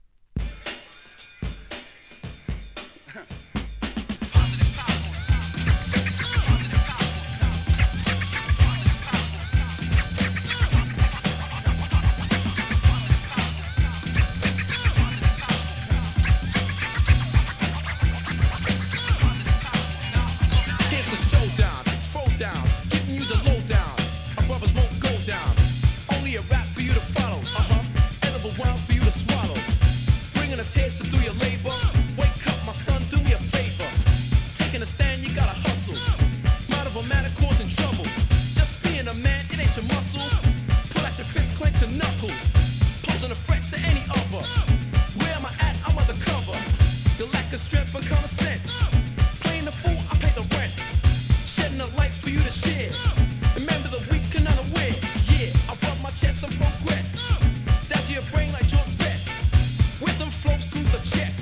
HIP HOP/R&B
1992年、疾走感溢れるUK NEW SCHOOL!!